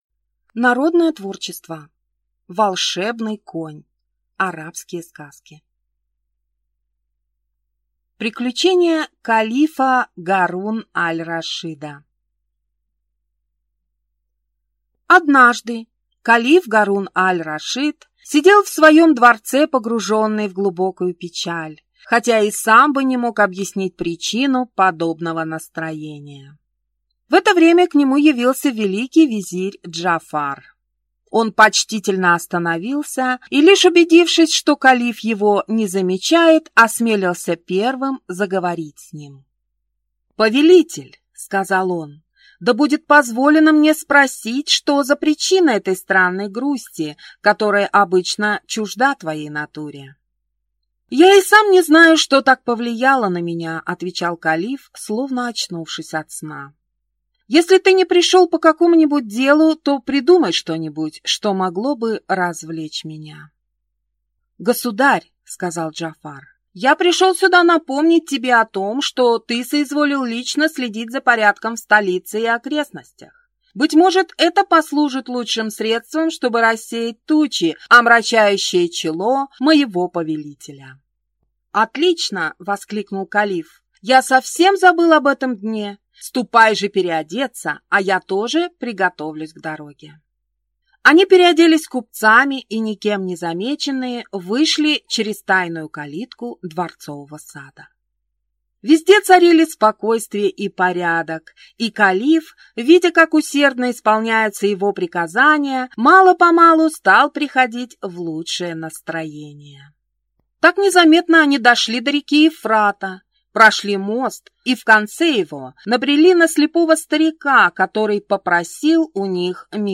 Аудиокнига Волшебный конь: арабские сказки | Библиотека аудиокниг